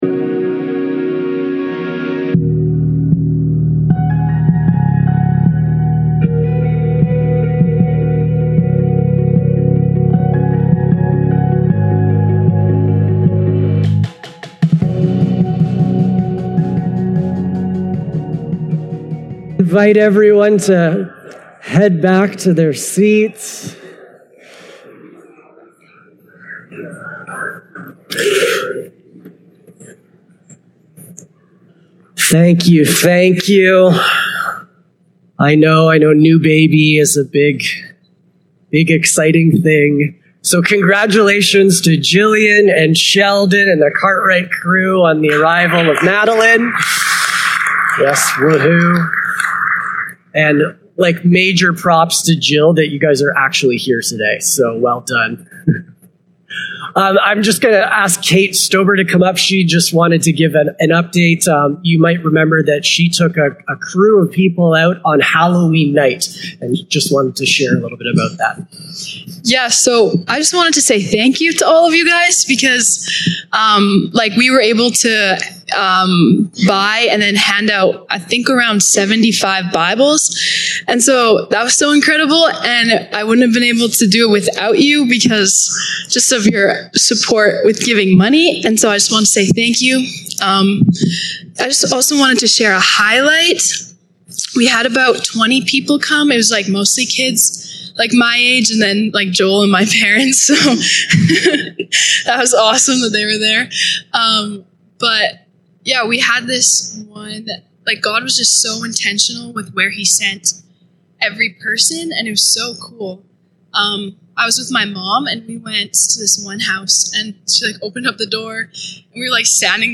Sermons | Unity Baptist Church